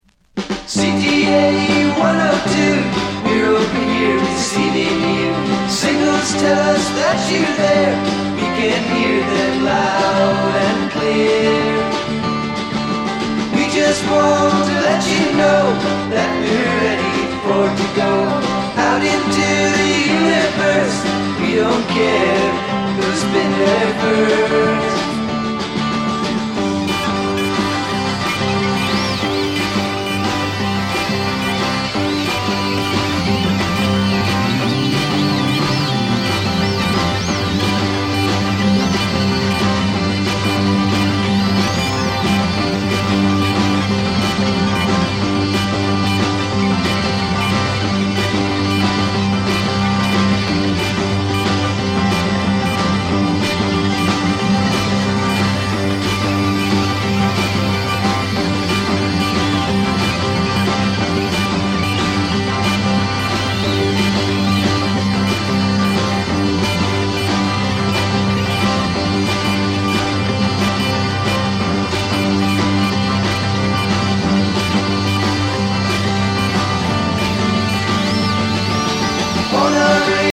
西海岸フォークロックバンド